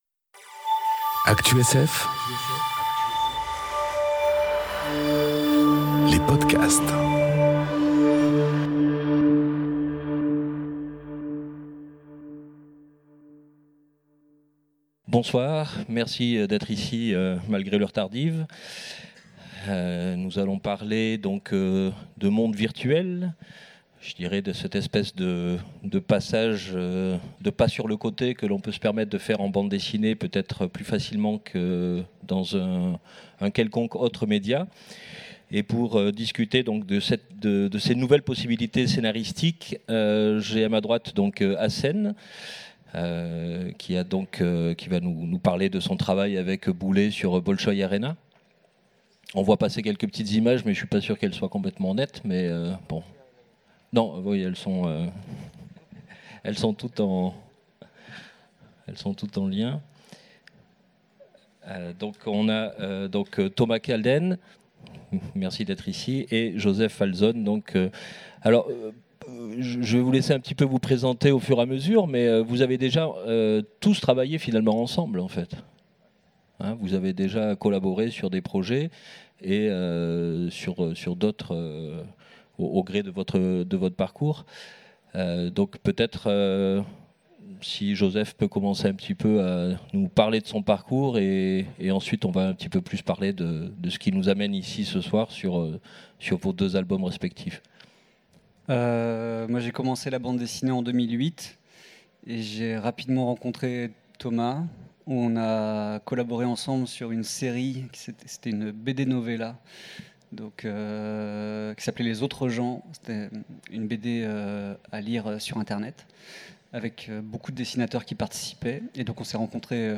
Conférence À l'aube des mondes virtuels enregistrée aux Utopiales 2018